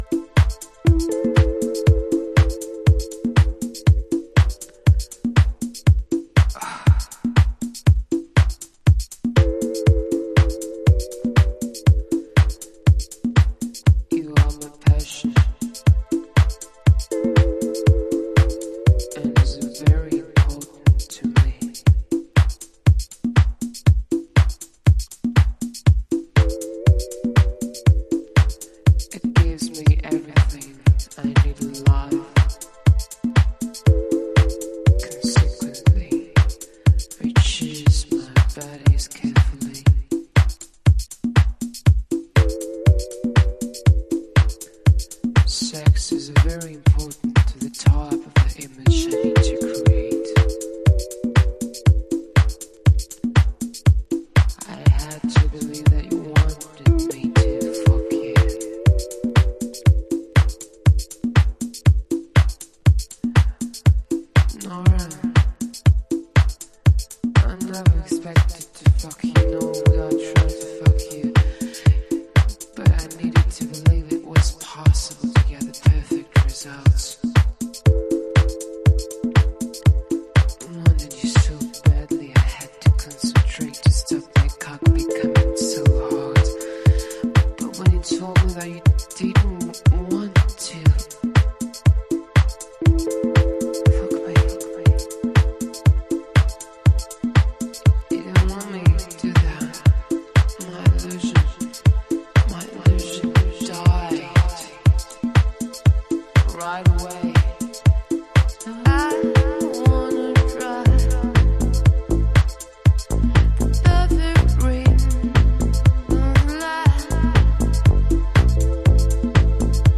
淡々と紡ぐビートにすけべえなウィスパー・ヴォイス
弄りベースライン